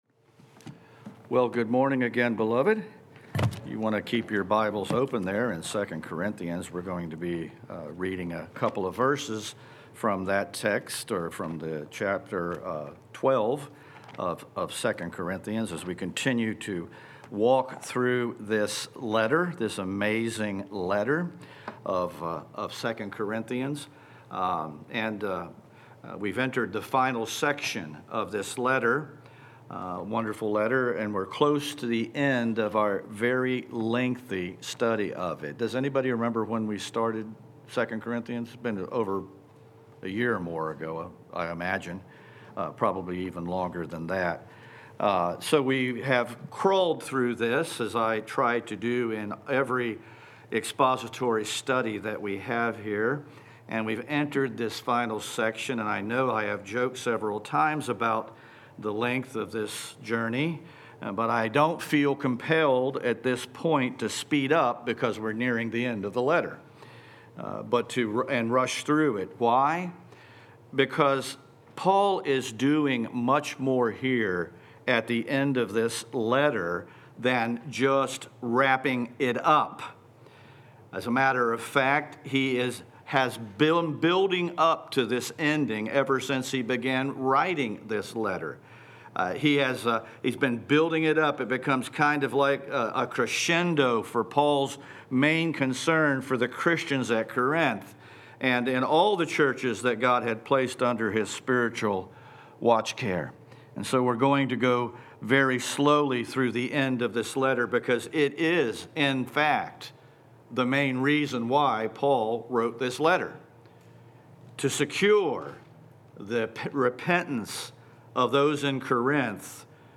Sermon_05-18-25.mp3